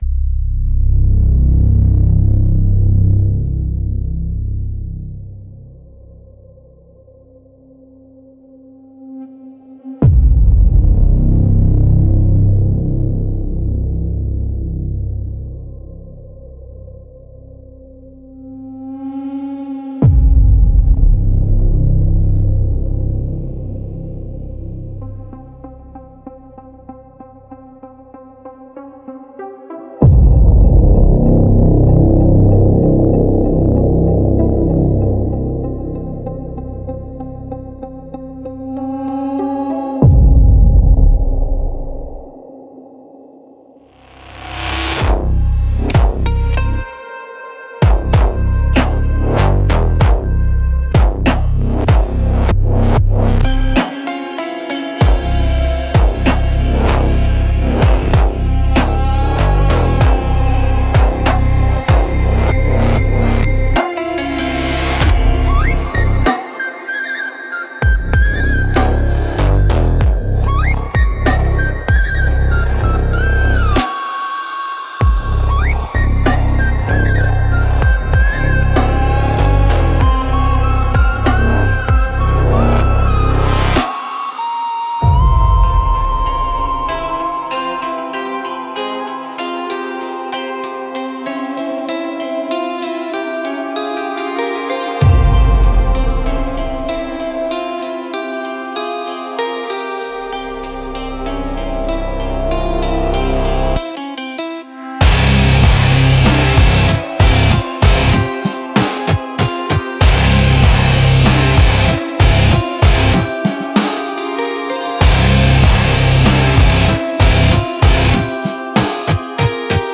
Instrumentals - The Uncoiling Spring